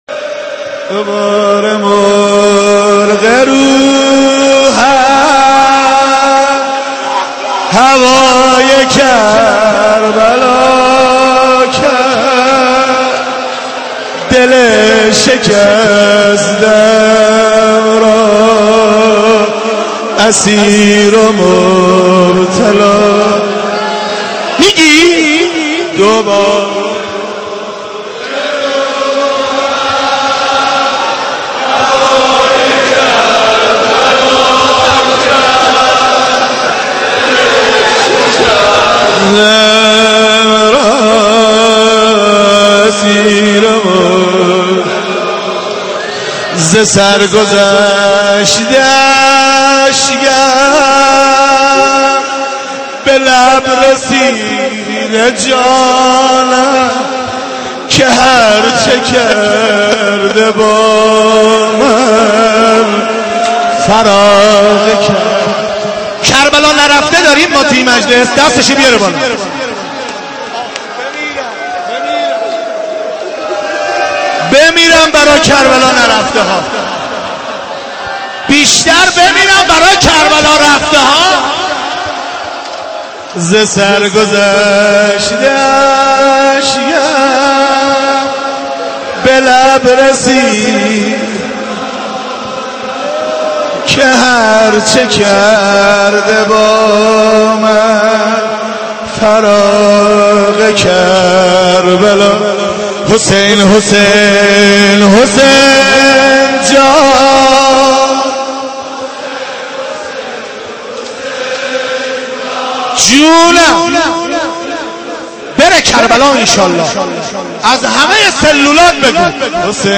Madahi-2.mp3